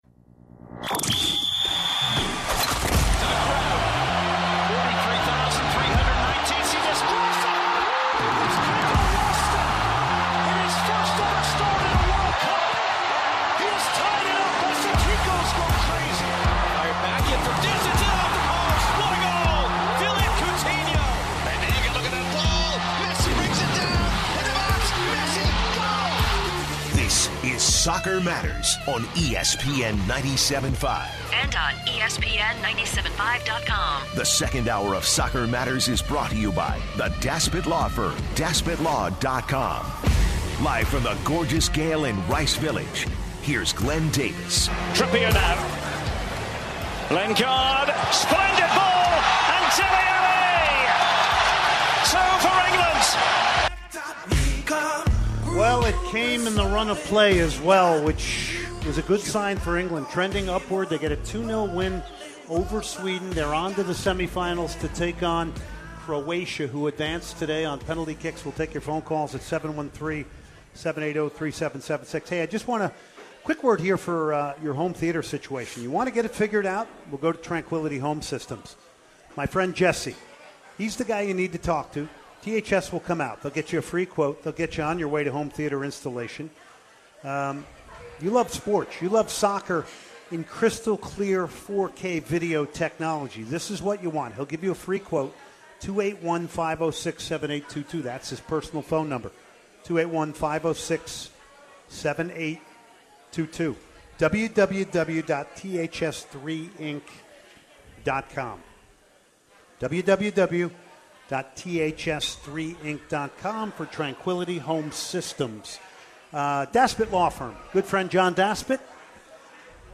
As the half continues, he takes a couple calls discussing today’s games as well as Europe’s dominance in the tournament.